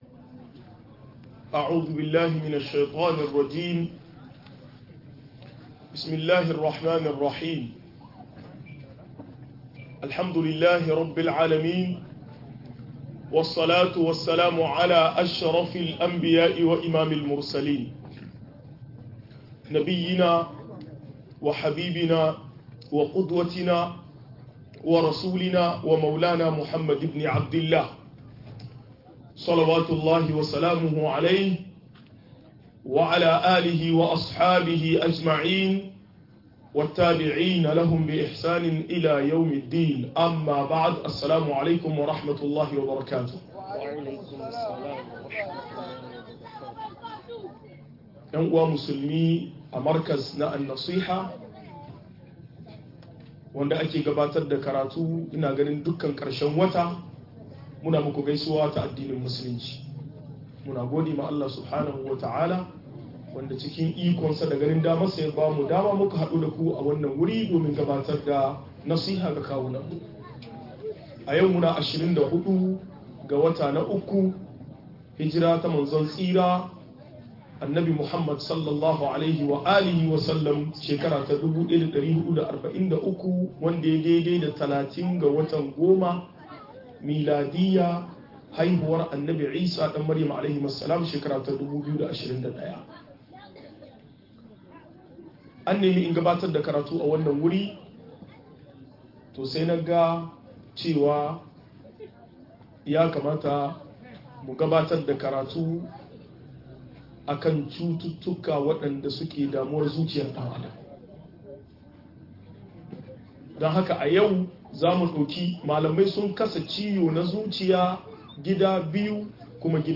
Mahanga ta Muslunci game da sha'awa - MUHADARA